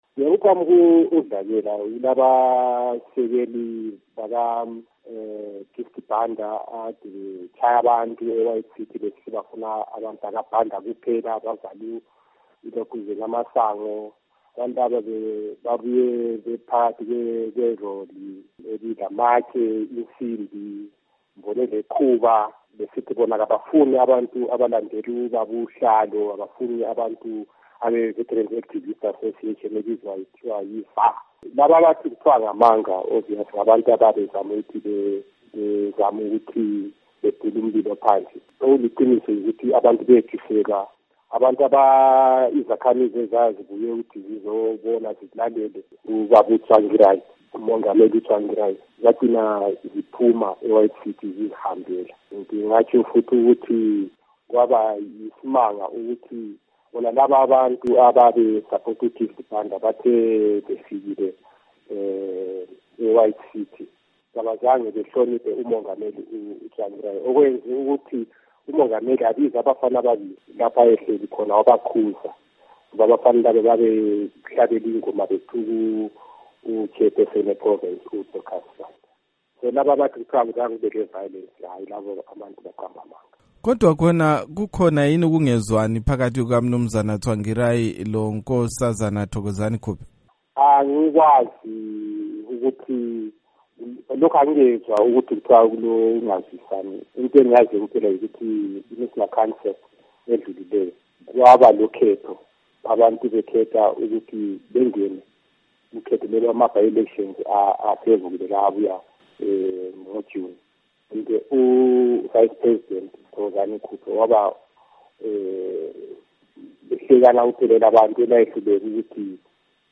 Ingxoxo LoMnu.